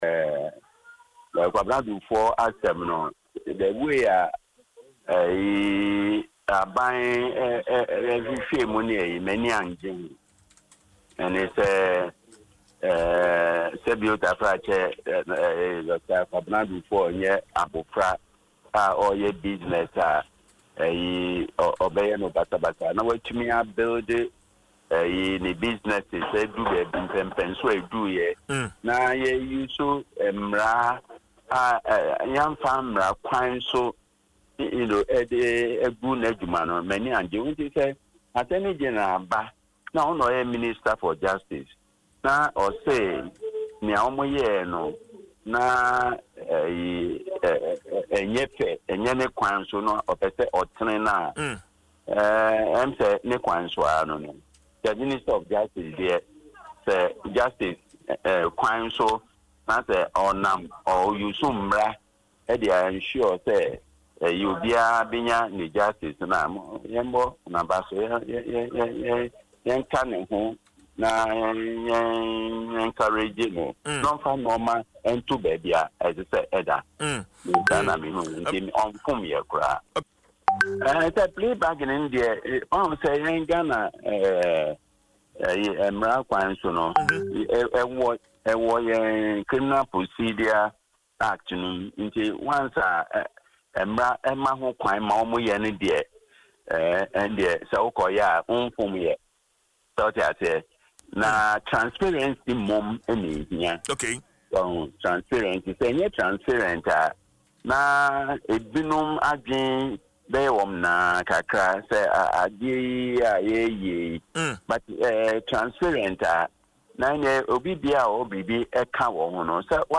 Speaking in an interview on Adom FM’s morning show, Dwaso Nsem, the seasoned lawyer stated that the Attorney-General acted within the law and should not be criticised.